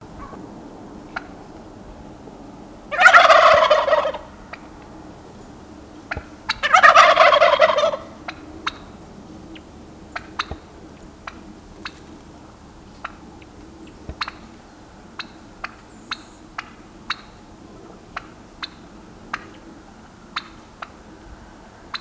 Громкий крик индюка